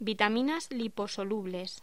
Locución: Vitaminas liposolubles